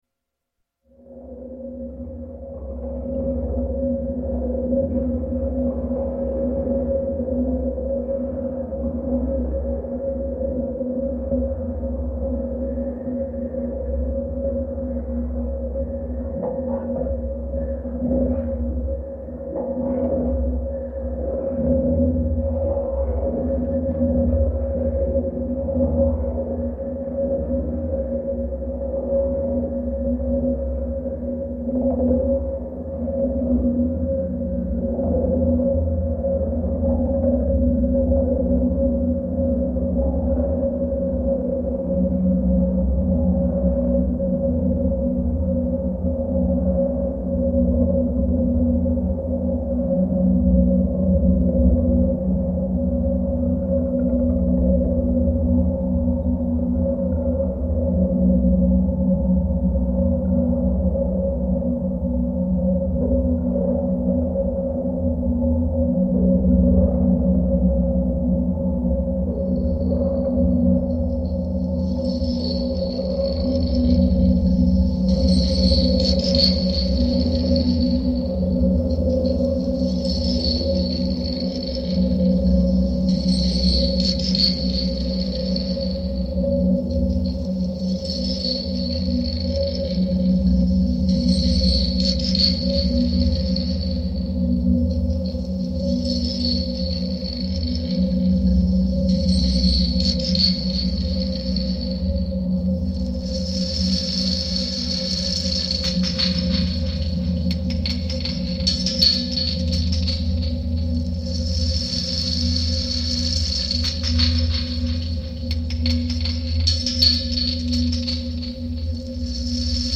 Abandoned olive press in Benfeita, Portugal reimagined